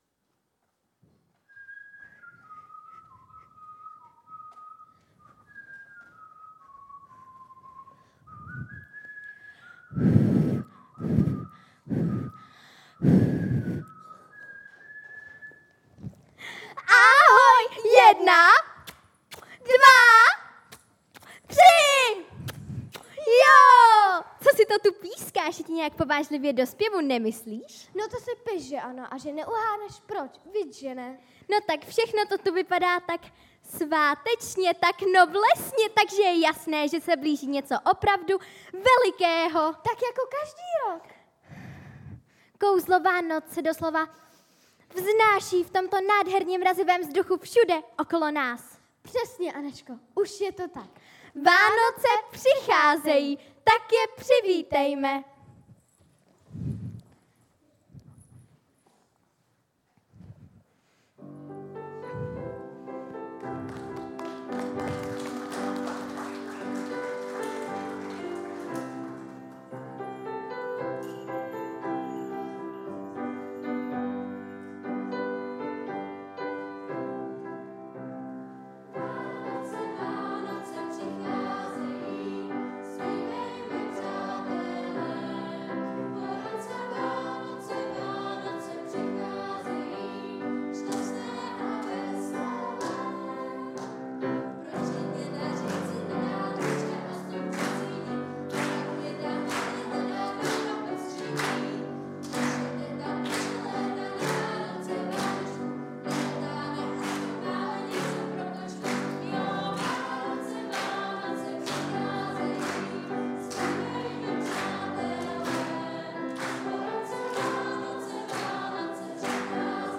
Dětská vánoční slavnost